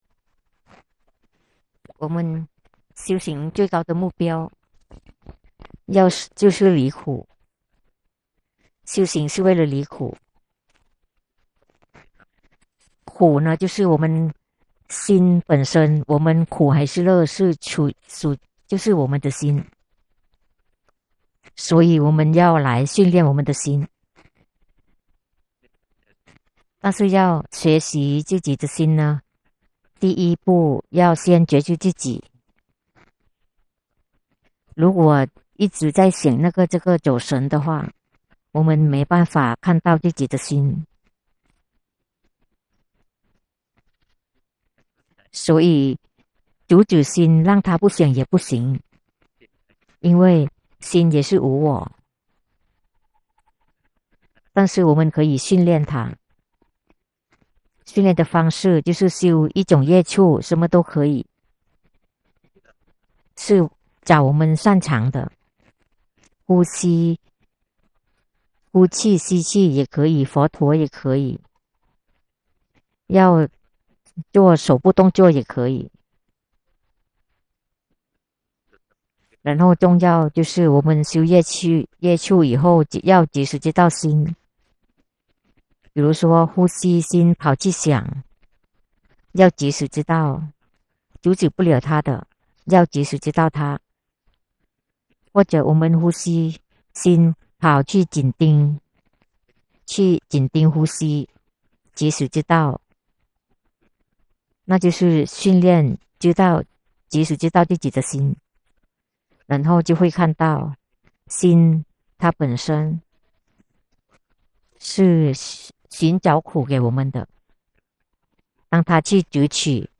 法談摘錄
同聲翻譯